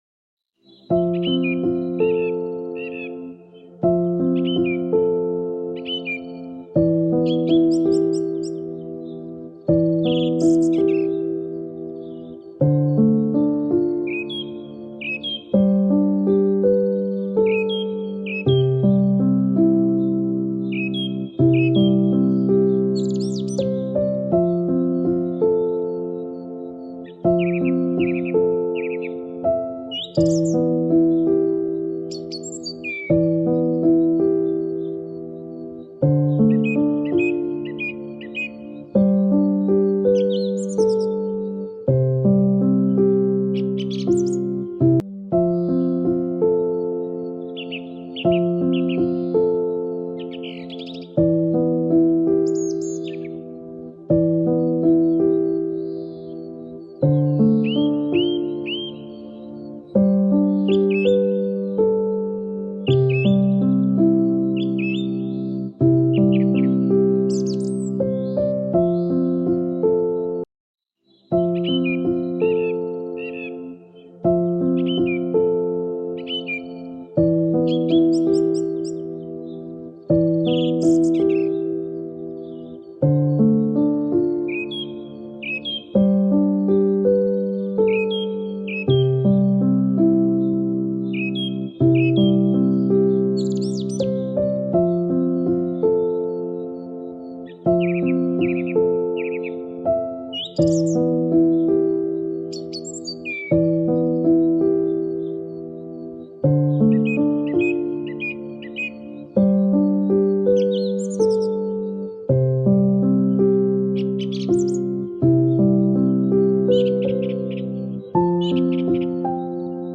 DUNKLE ENTSPANNUNG: Sommernacht-Waldkonzert vereint Grillen + Eulen